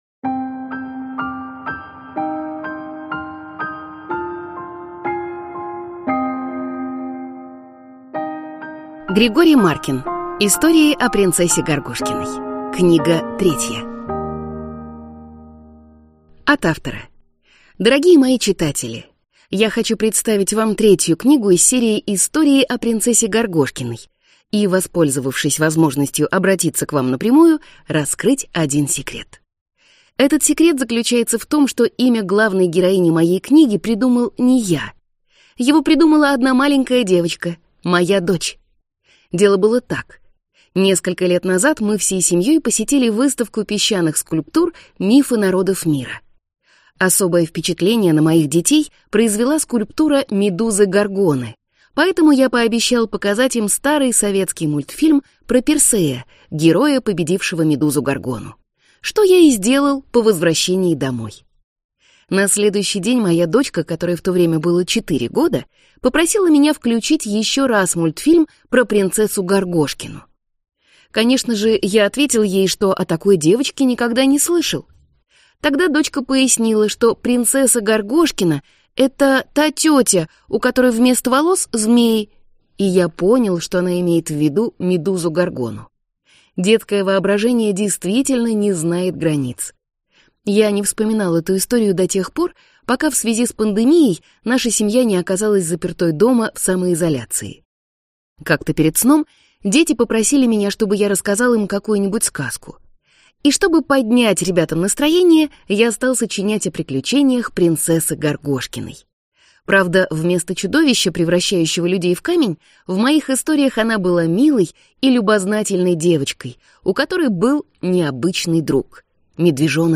Аудиокнига Истории о принцессе Горгошкиной. Книга третья | Библиотека аудиокниг